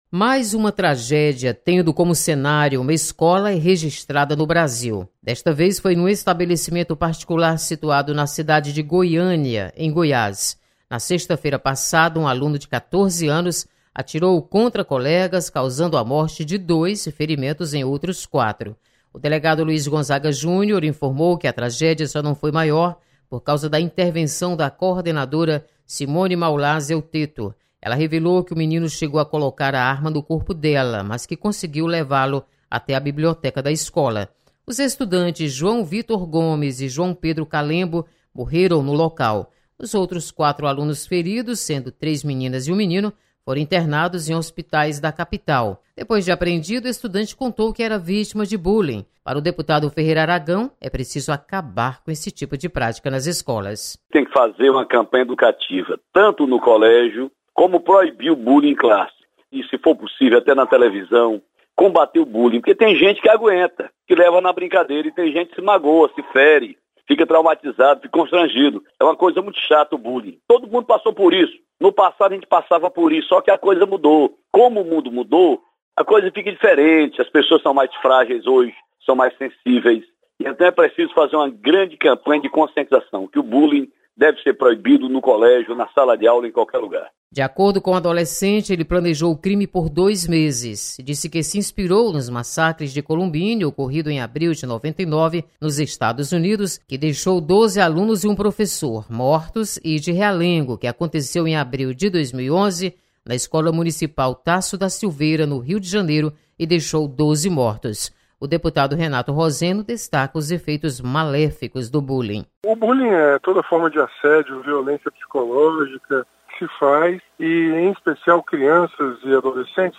Deputados comentam sobre caso de Goiânia.